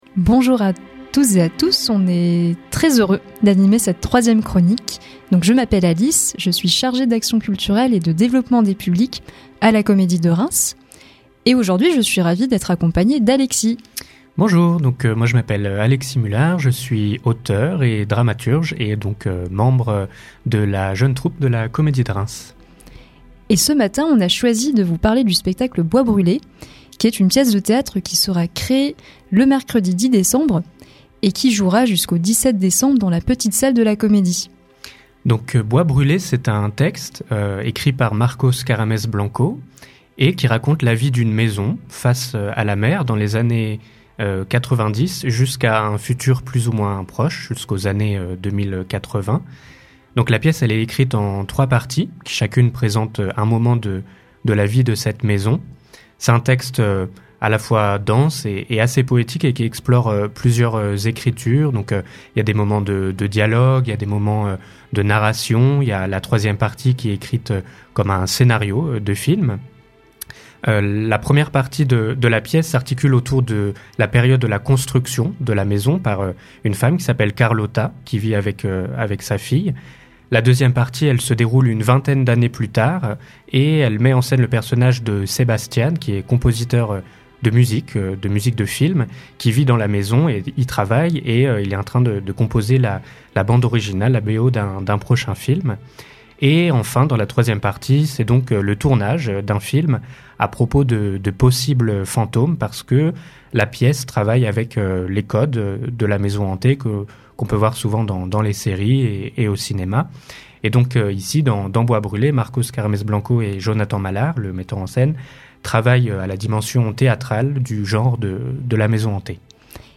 Chronique du 03 décembre (7:06)